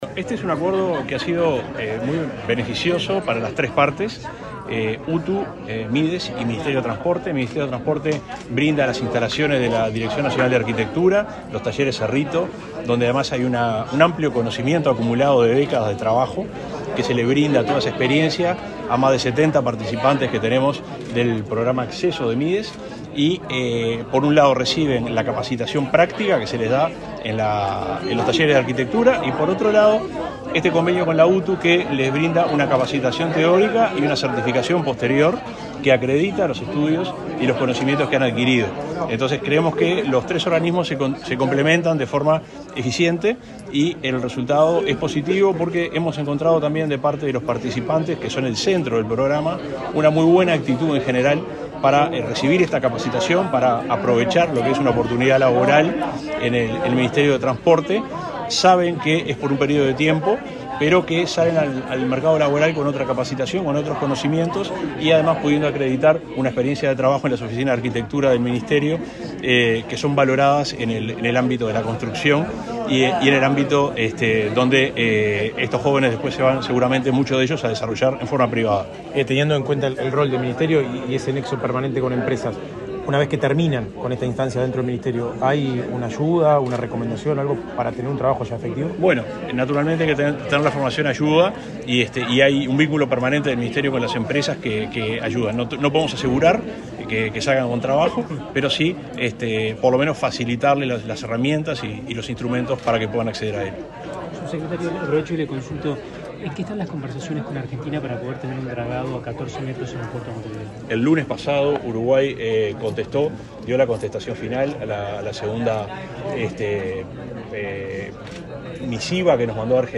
Declaraciones del subsecretario de Transporte
Este jueves 6, el subsecretario de Transporte, Juan José Olaizola, dialogó con la prensa luego de participar en la firma de un convenio entre esa